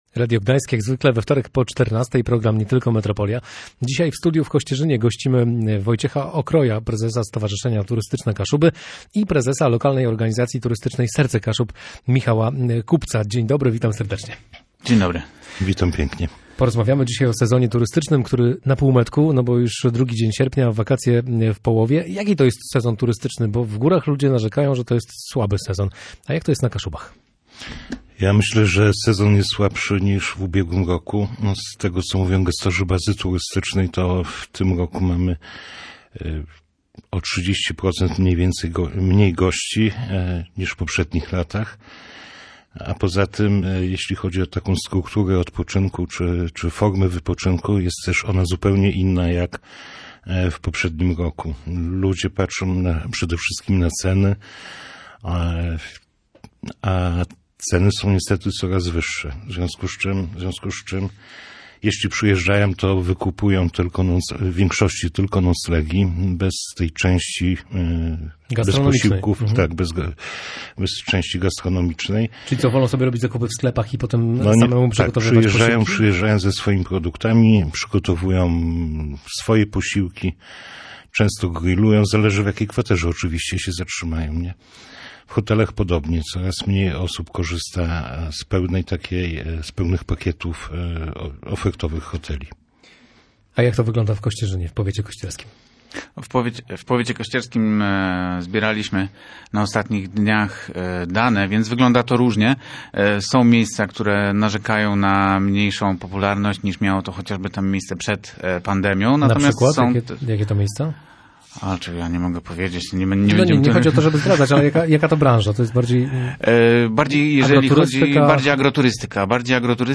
lokalnych specjalistów od turystyki